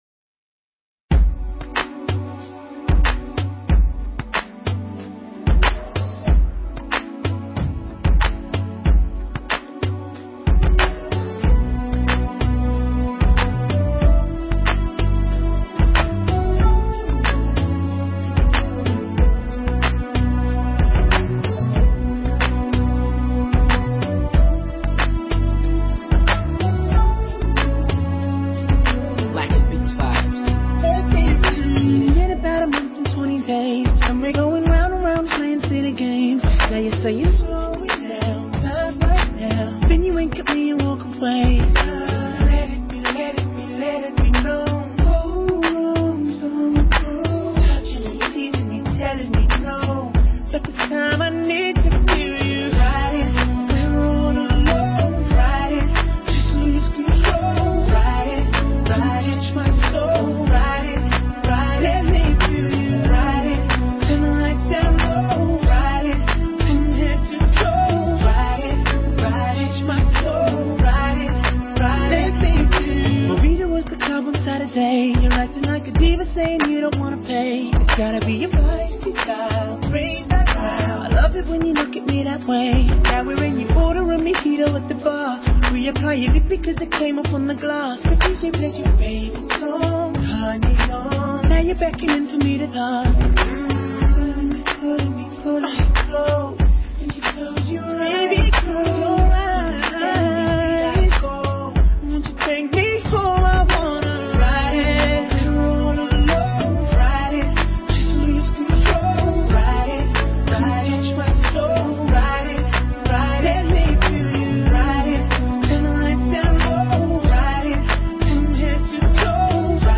певец, композитор
поп, R&B, Хип-хоп, Соул, Бангра.